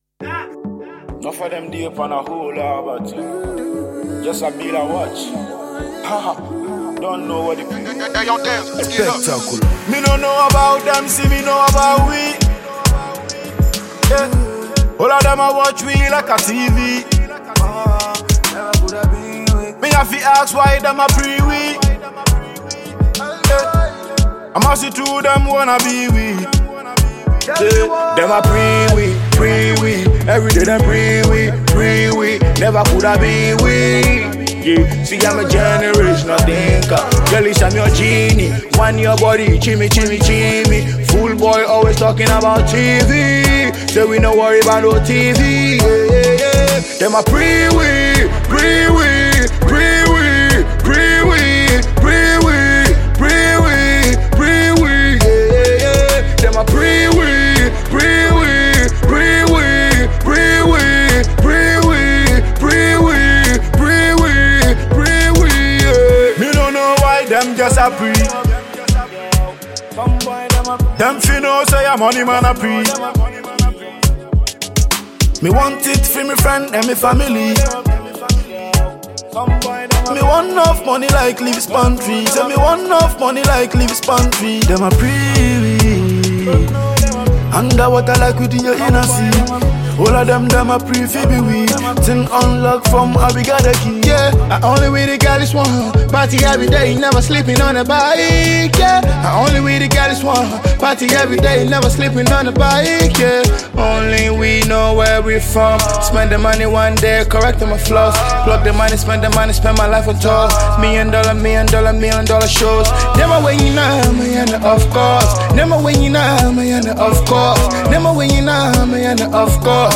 Ghana Music
Ghanaian highly-rated afrobeat/afropop artiste